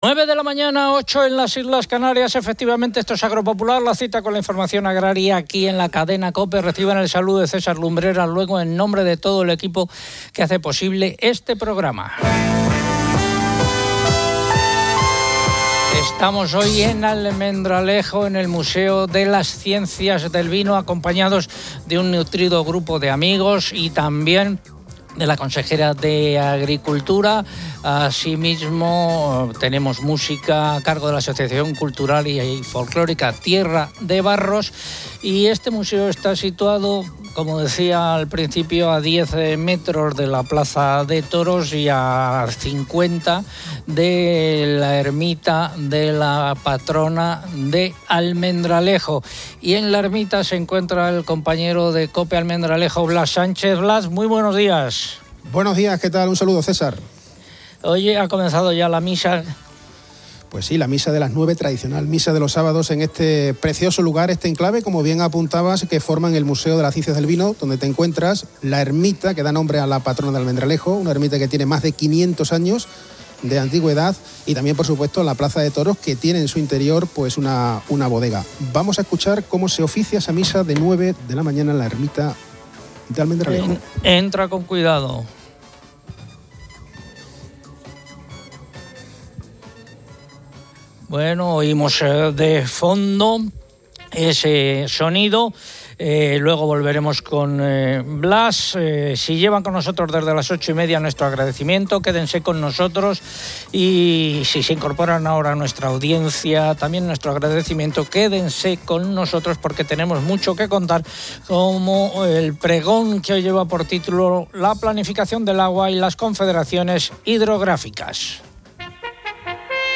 Estamos hoy en Almendralejo, en el Museo de las Ciencias del Vino, acompañados de un nutrido grupo de amigos y también de la consejera de Agricultura. Asimismo, tenemos música a cargo de la Asociación Cultural y Folclórica Tierra de Barros.